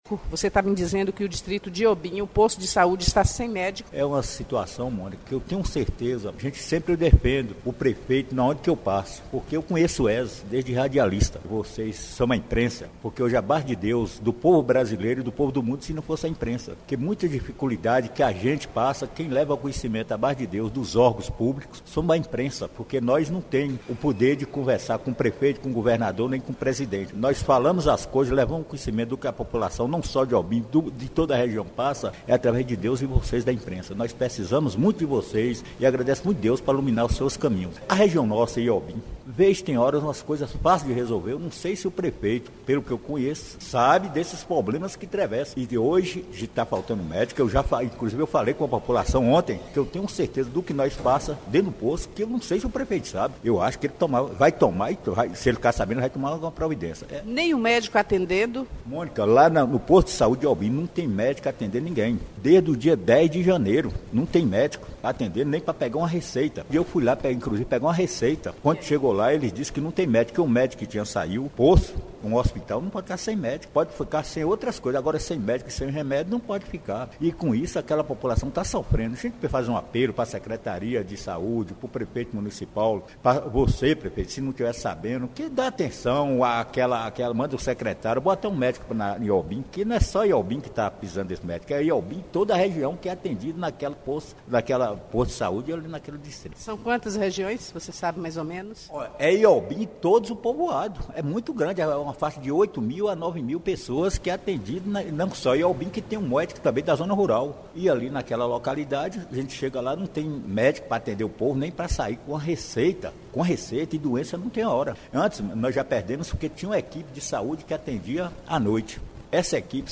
Em entrevista à Rádio Clube de Conquista que o BLOG DO ANDERSON repercute nesta terça-feira (12) duas lideranças apontaram sérios problemas. O primeiro fica para a Unidade de Saúde da Família Inhobim que está sem médicos desde o início do ano. Outra queixa é sobre o abandono das estradas.